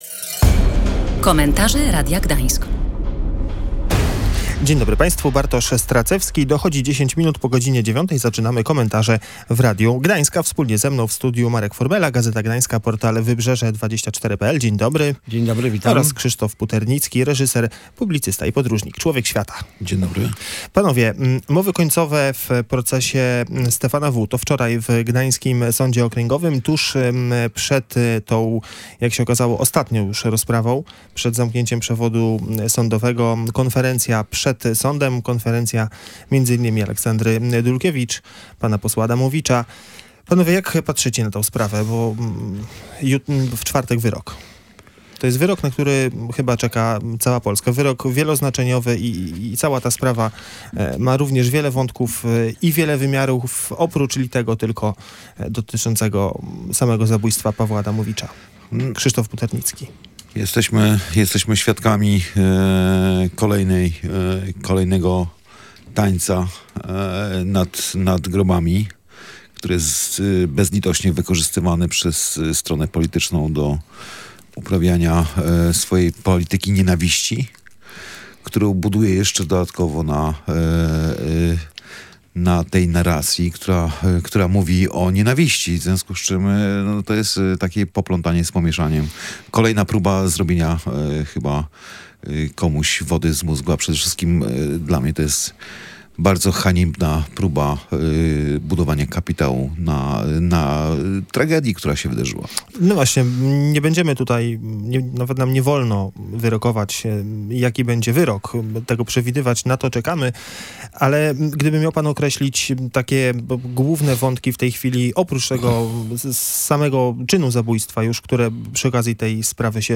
Czy te wypowiedzi polityków dowodzą, że złe słowo będzie w tej kampanii wyborczej grało pierwsze skrzypce? – zapytał prowadzący.